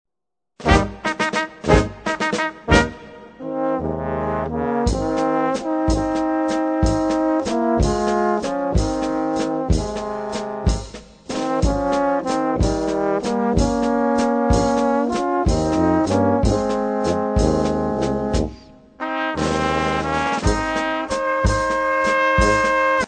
Current Location: Genre Blasmusik